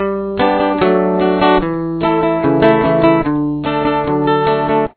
Chorus
This is a C, D, D/F#, G chord progression.